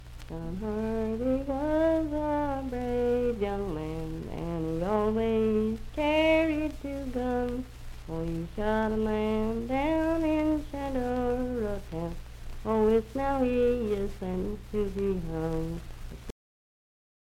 Unaccompanied vocal music
Minstrel, Blackface, and African-American Songs, Outlaws and Prisoners
Voice (sung)